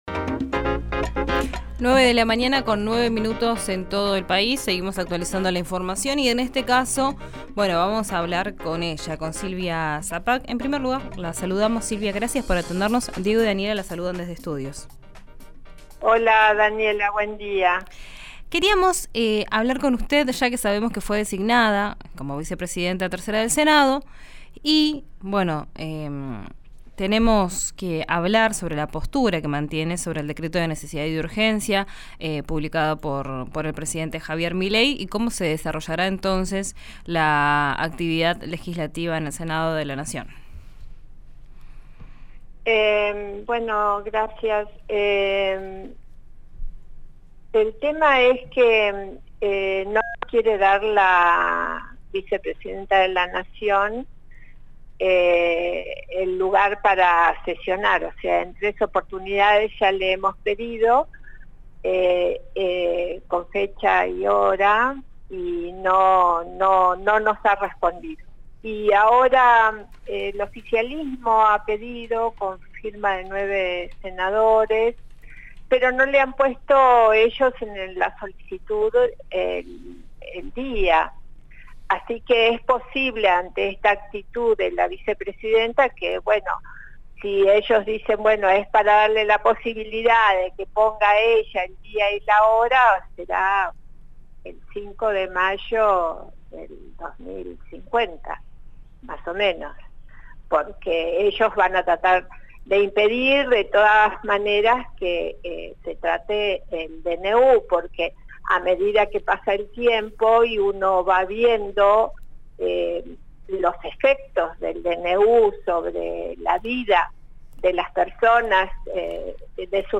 En diálogo con RÍO NEGRO RADIO, la senadora por Neuquén y referente de Unión por la Patria cuestionó al mandatario y a la vicepresidenta Victoria Villarruel por no convocar al tratamiento del DNU.
Escuchá a la senadora Silvia Sapag en RÍO NEGRO RADIO: